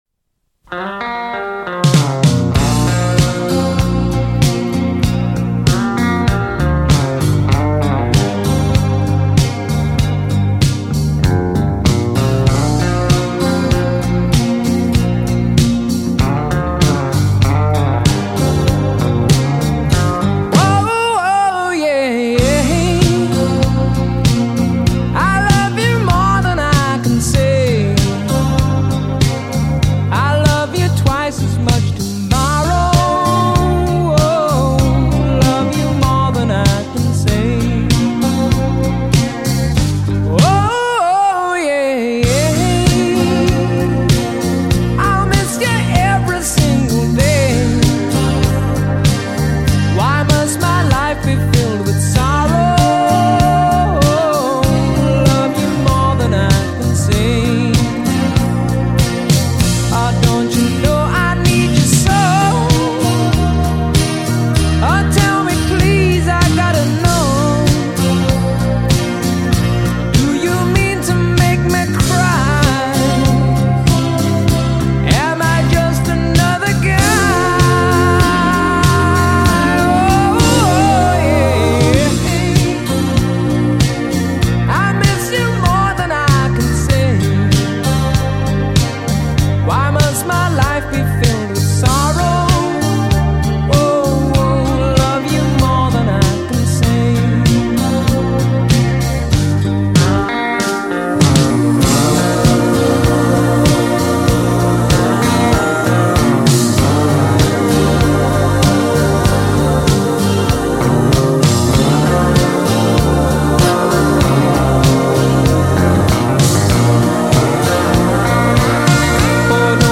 极品发烧 汽车音乐典范